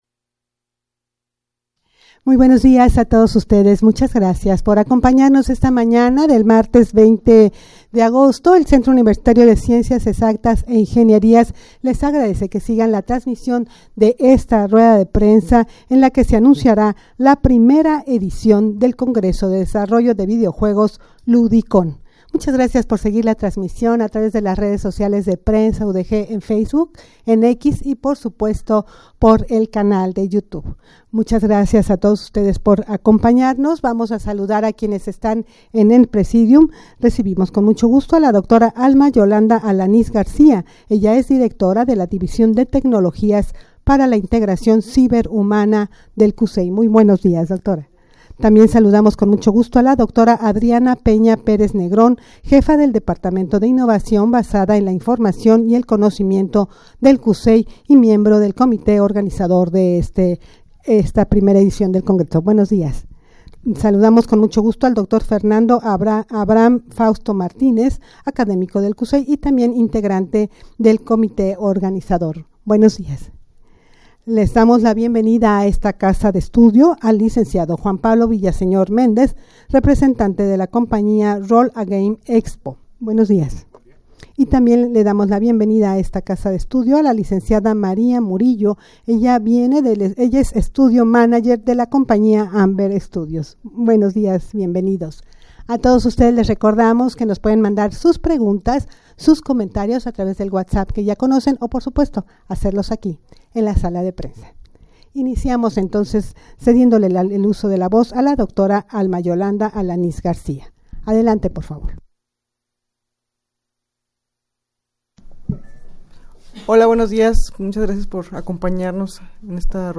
Audio de la Rueda de Prensa
rueda-de-prensa-en-la-que-se-anunciara-la-primera-edicion-del-congreso-de-desarrollo-de-videojuegos-ludicon.mp3